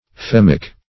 fehmic - definition of fehmic - synonyms, pronunciation, spelling from Free Dictionary Search Result for " fehmic" : The Collaborative International Dictionary of English v.0.48: Fehmic \Feh"mic\, a. See Vehmic .